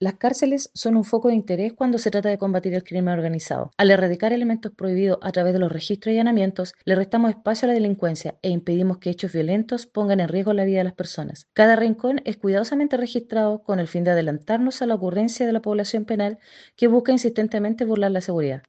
La Directora Regional de Gendarmería, Coronel Angélica Briones, valoró el operativo realizado, que dijo le resta espacio a la delincuencia.